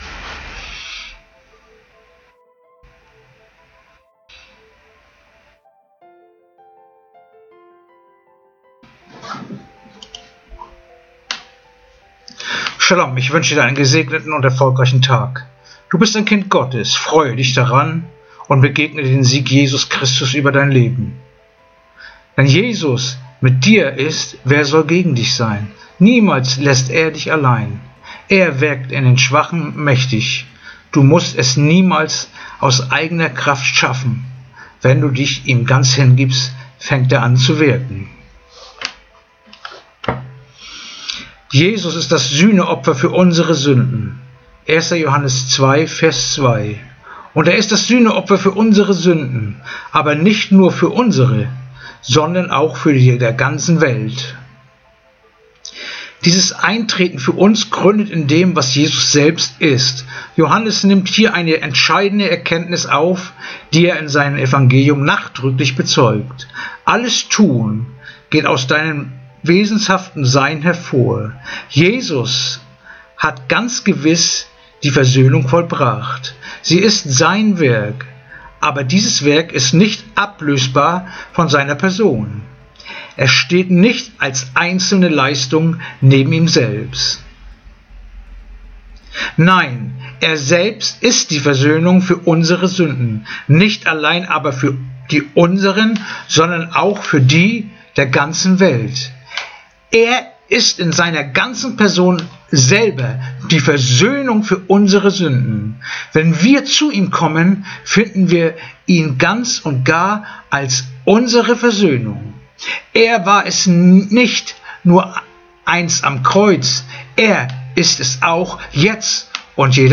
Andacht-vom-14-April-11-Johannes-2-2